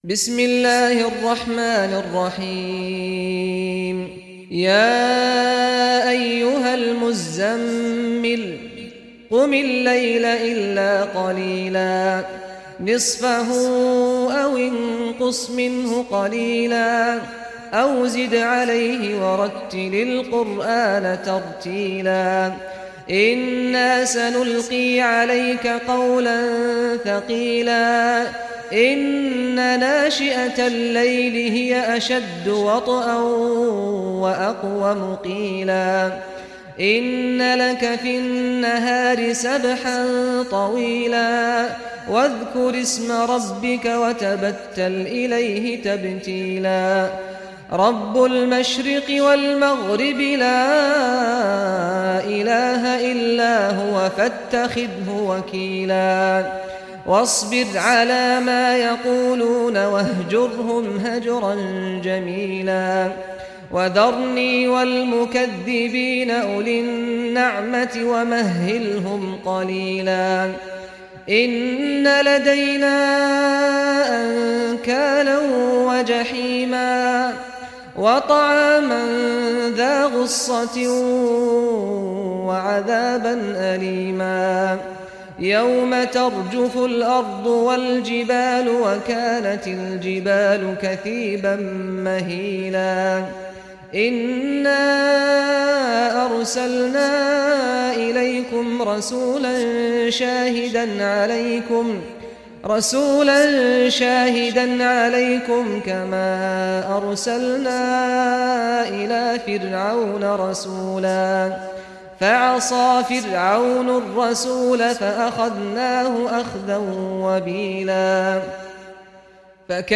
Surah Muzammil Recitation by Sheikh Saad Ghamdi
Surah Muzammil, listen or play online mp3 tilawat / recitation in Arabic in the beautiful voice of Sheikh Saad al Ghamdi.